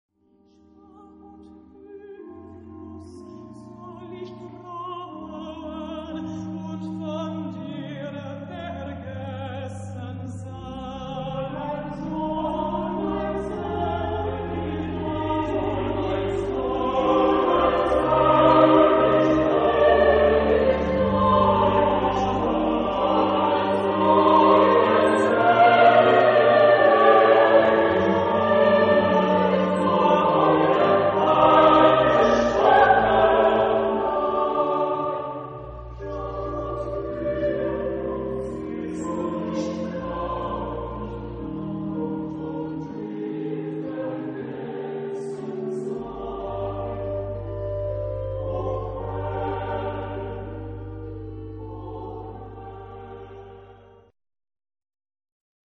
Genre-Stil-Form: geistlich ; romantisch ; Chor
Chorgattung: SATB  (4 gemischter Chor Stimmen )
Solisten: Alto (1)  (1 Solist(en))
Instrumentation: Orgel  (1 Instrumentalstimme(n))
Tonart(en): Es-Dur